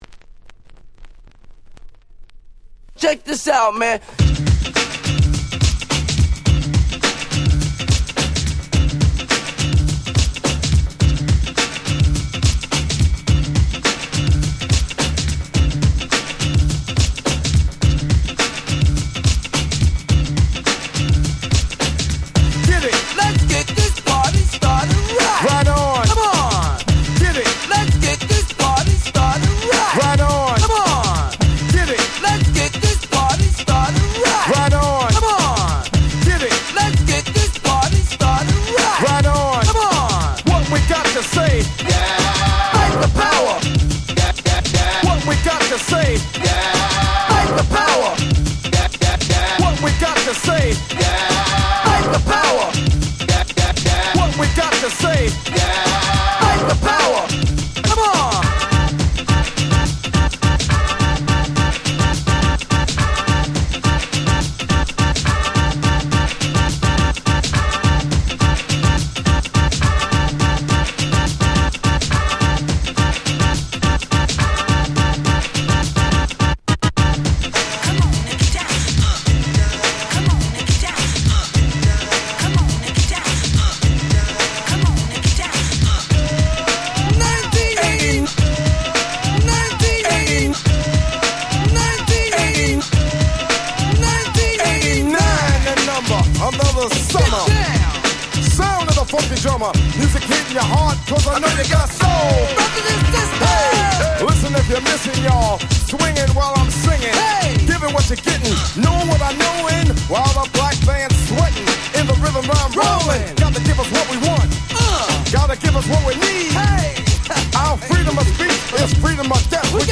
89' Nice Remix !!